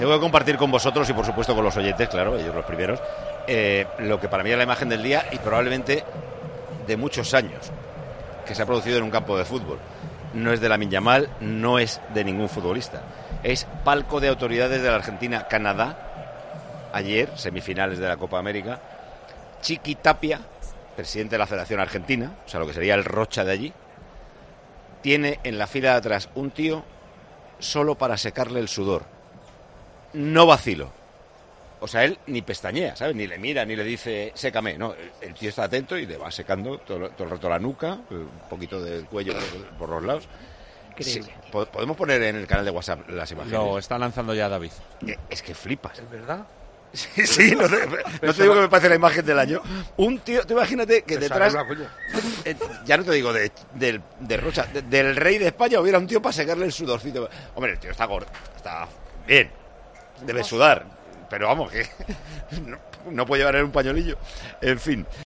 Entre tanto partido importante, Paco González, director y presentador de Tiempo de Juego, se fijó en una de las imágenes que ha dejado el mundo del fútbol durante las últimas horas y compartió su opinón con los oyentes al comienzo del programa de este miércoles antes de la disputa del partido entre Países Bajos e Inglaterra.